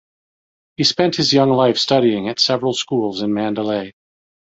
Leer más Significado (Inglés) A city in central Burma (Myanmar) Conceptos Mandalay Frecuencia 42k Con guion como Man‧da‧lay Pronunciado como (IPA) /ˌmæn.dəˈleɪ/ Etimología (Inglés) Borrowed from Burmese မန္တလေး (manta.le:).